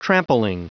Prononciation du mot trampling en anglais (fichier audio)
Prononciation du mot : trampling